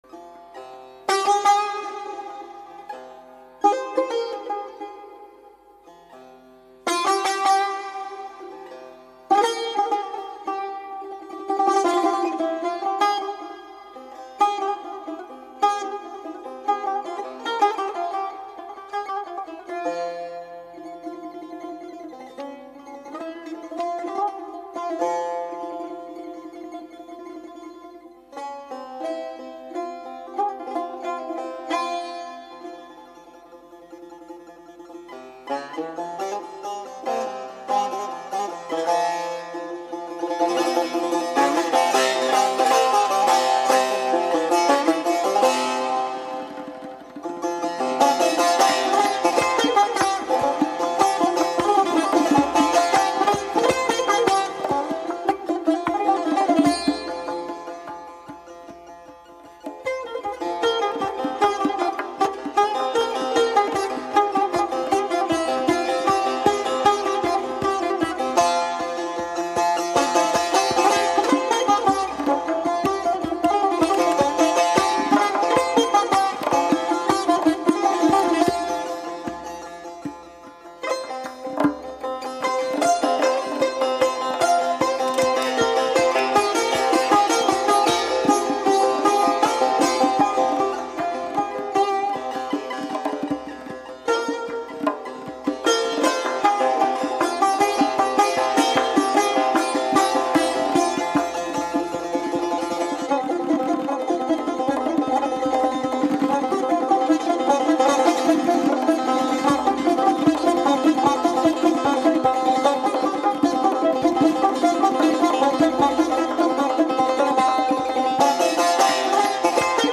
ده سال پیش در مشهد
تار
در دستگاه راست پنجگاه